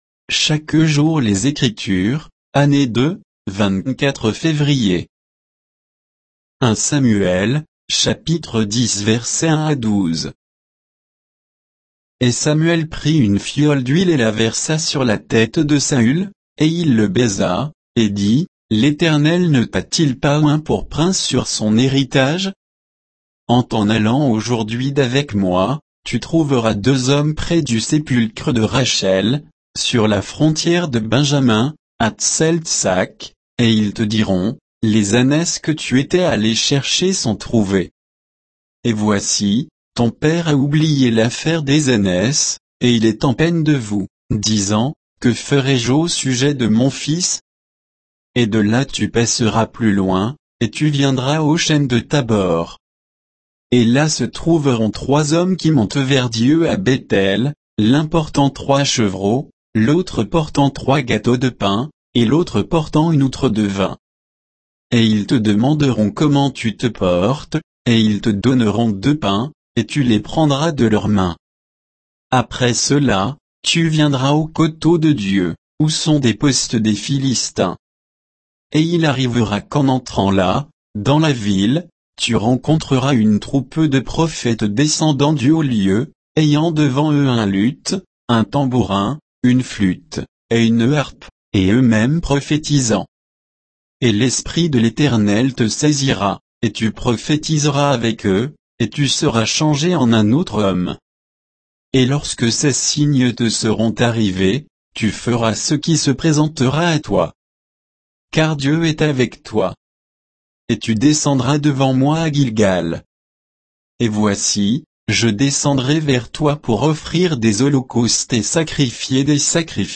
Méditation quoditienne de Chaque jour les Écritures sur 1 Samuel 10